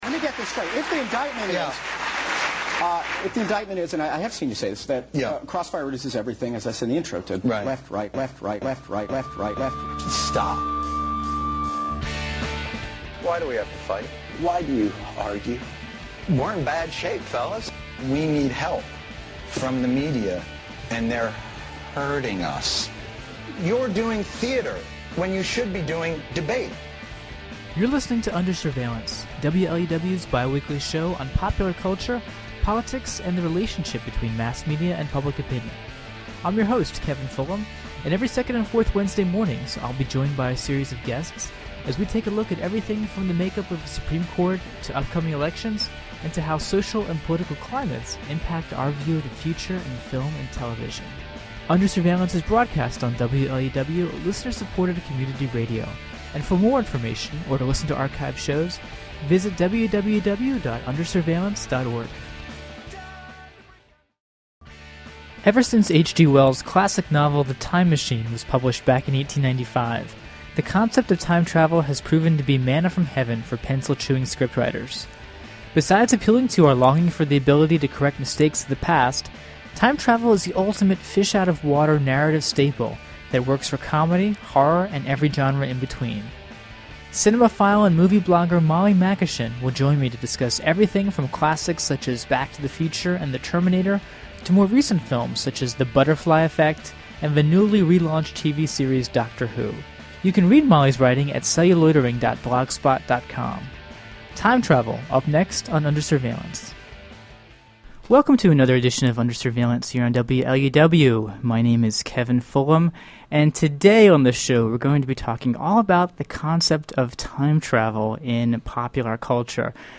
[Originally broadcast on WLUW’s Under Surveillance in July 2008.] https